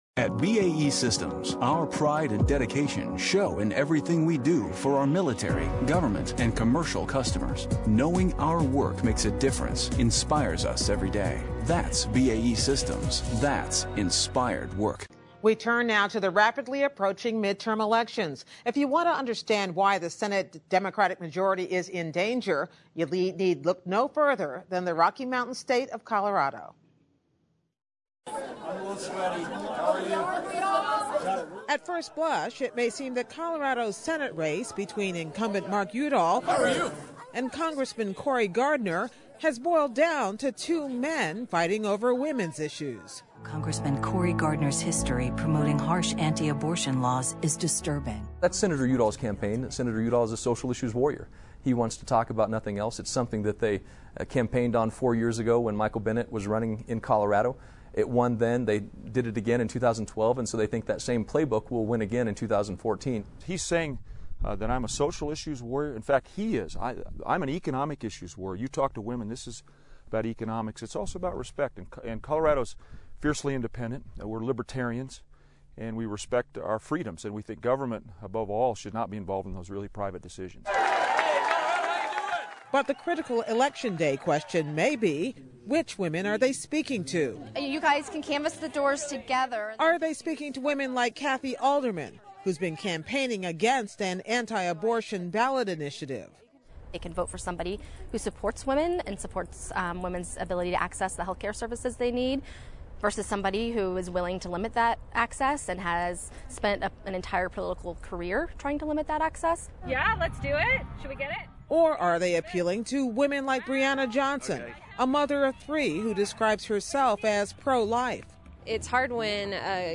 Gwen Ifill reports on the many factors making Colorado’s election unpredictable.